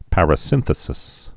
(părə-sĭnthĭ-sĭs)